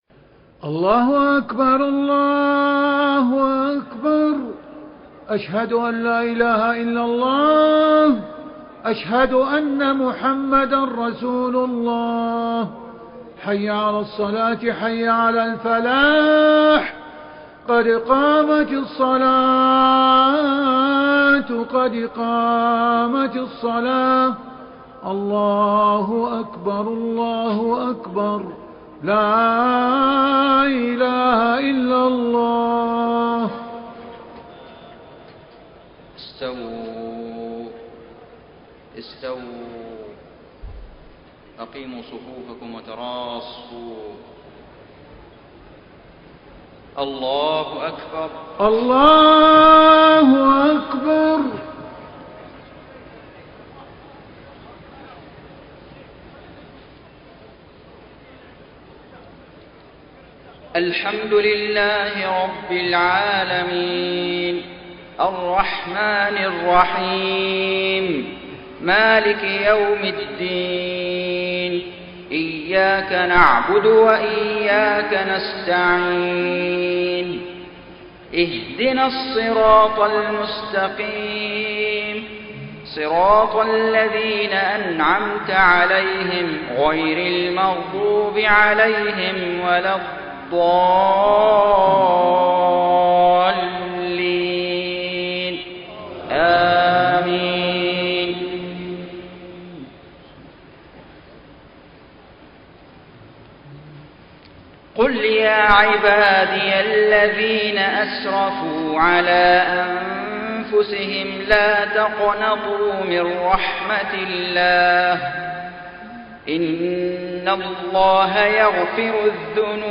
صلاة العشاء 6 ذو القعدة 1432هـ من سورة الزمر 53-67 > 1432 🕋 > الفروض - تلاوات الحرمين